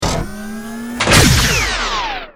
battlesuit_hugelaser.wav